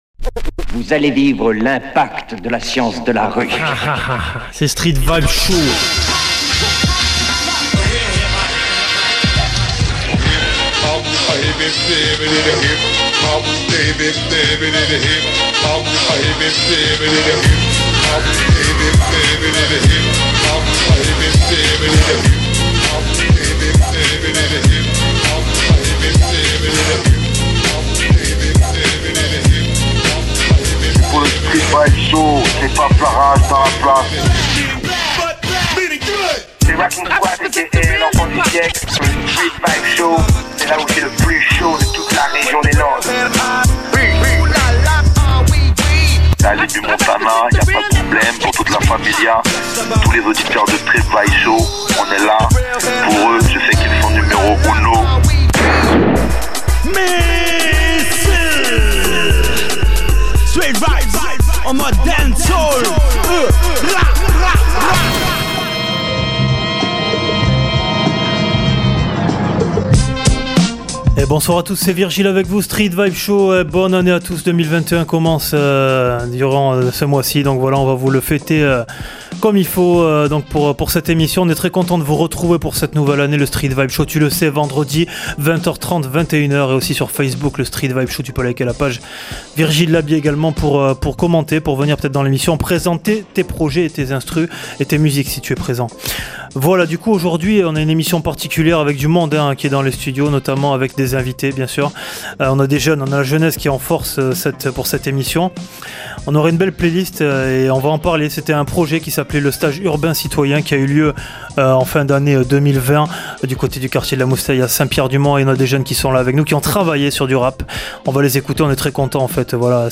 Hip Hop En savoir plus